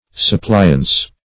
Suppliance \Sup*pli"ance\, n. [From Supply.]